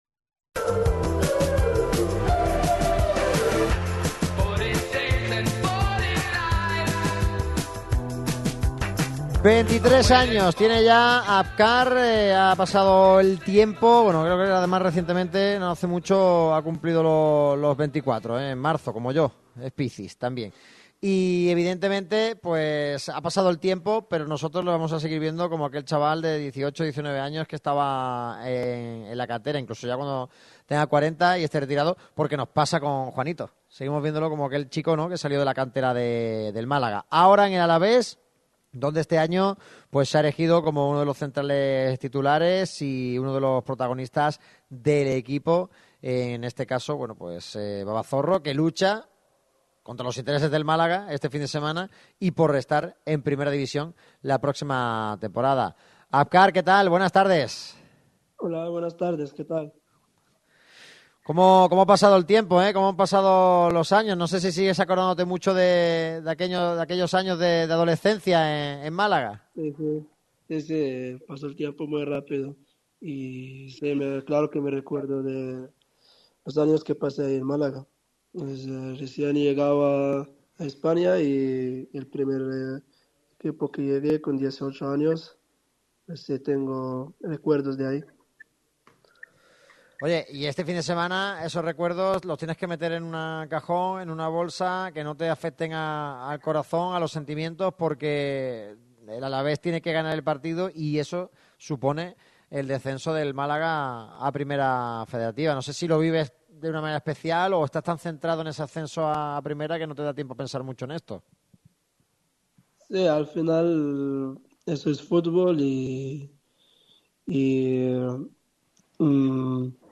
Sertasa ha sido el escenario donde Radio Marca Málaga ha emitido su programa